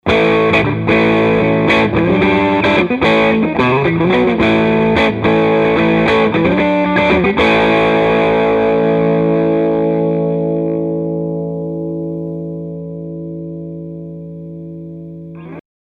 Dirty – Both Pickups
Clean clips were played in the Clean channel of my Fender Hot Rod Deluxe, while the dirty clips were played in the Drive channel.
dirty-both.mp3